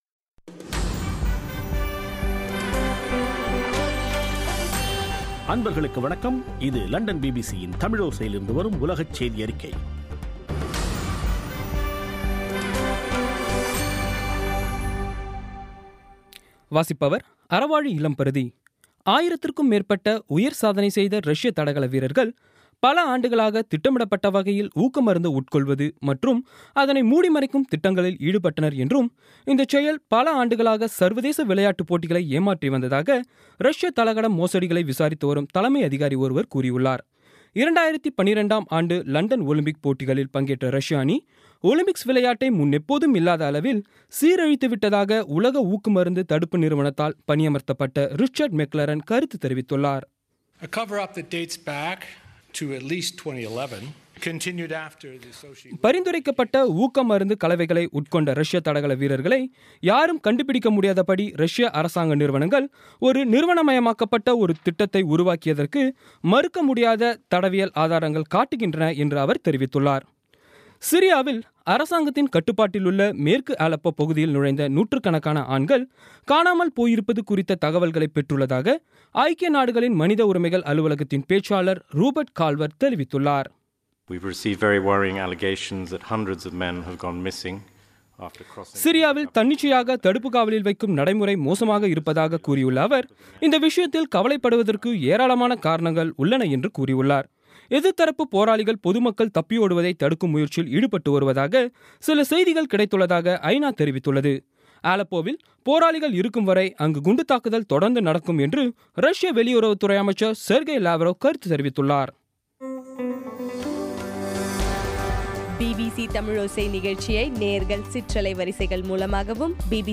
பி பி சி தமிழோசை செய்தியறிக்கை (09/12/16)